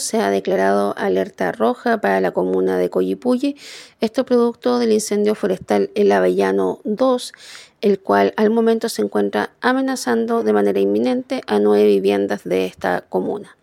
Hasta el momento el incendio forestal denominado “El Avellano 2” ha consumido 2 hectáreas, sin embargo, amenaza 9 viviendas y por lo mismo se declaró Alerta Roja para Collipulli. Así lo dijo la directora (s) de Senapred en La Araucanía, Catedrin Savaria.